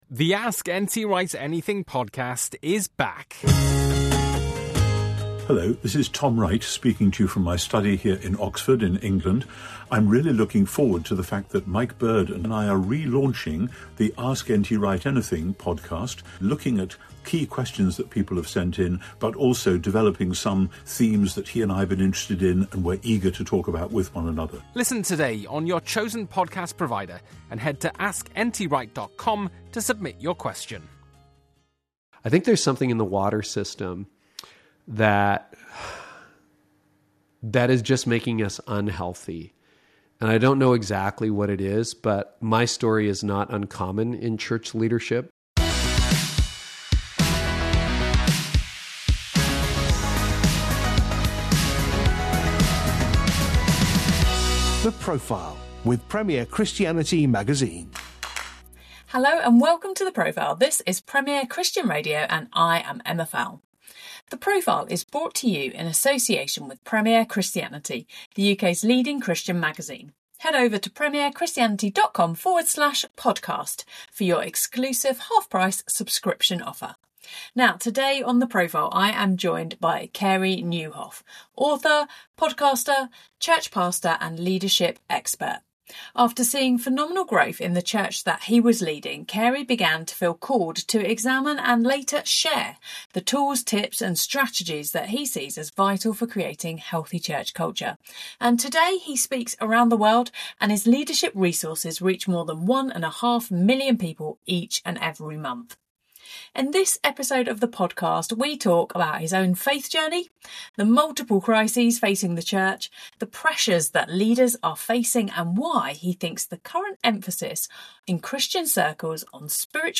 The Profile brings you in-depth interviews to help you learn from the wisdom and experiences of people who have gone all-in for God.